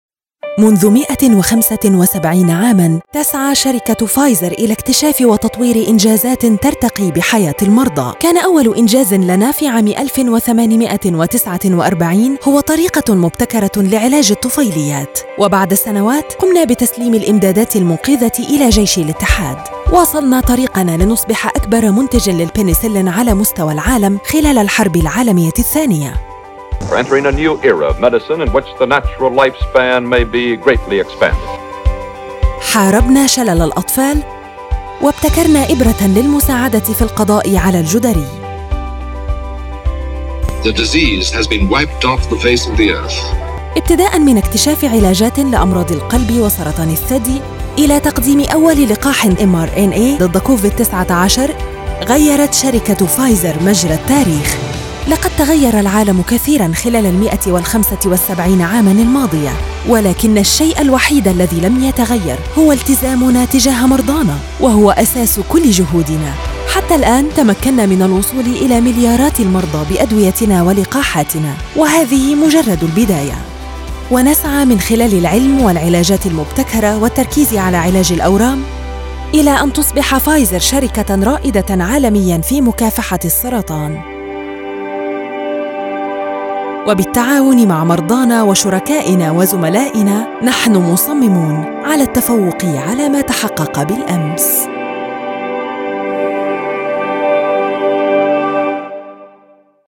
Narration Voice over in Arabic
Voiceover Voice Female Narration Arabic